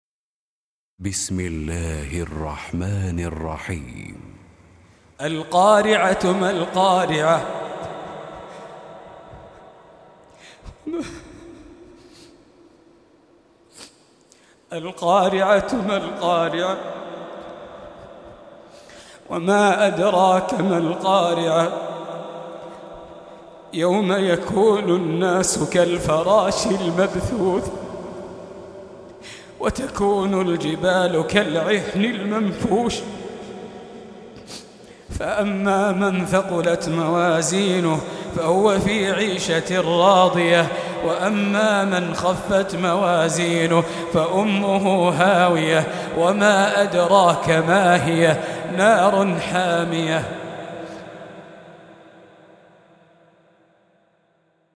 101. Surah Al-Q�ri'ah سورة القارعة Audio Quran Tarteel Recitation
Surah Repeating تكرار السورة Download Surah حمّل السورة Reciting Murattalah Audio for 101. Surah Al-Q�ri'ah سورة القارعة N.B *Surah Includes Al-Basmalah Reciters Sequents تتابع التلاوات Reciters Repeats تكرار التلاوات